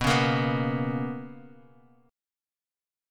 BM7sus2 chord